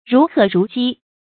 如渴如饑 注音： ㄖㄨˊ ㄎㄜˇ ㄖㄨˊ ㄐㄧ 讀音讀法： 意思解釋： 見「如饑如渴」。